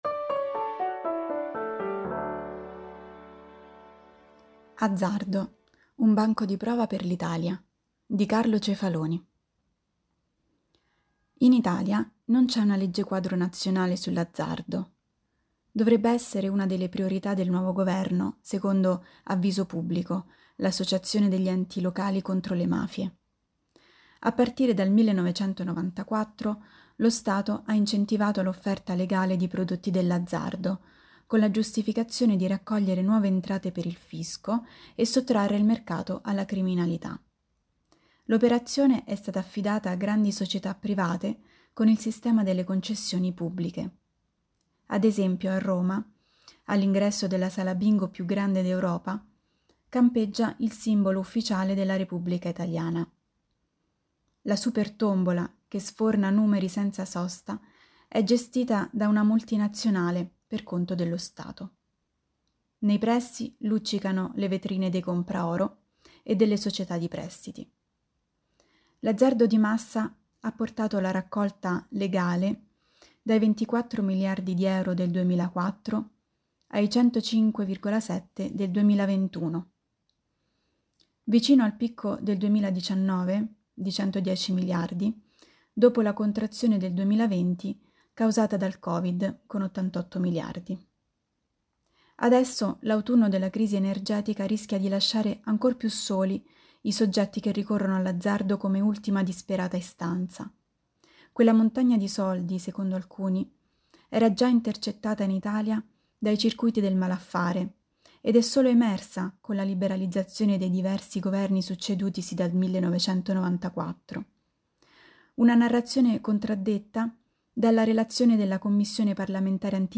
Per ogni numero, ci sarà una selezione di 10 articoli letti dai nostri autori e collaboratori.
Al microfono, i nostri redattori e i nostri collaboratori.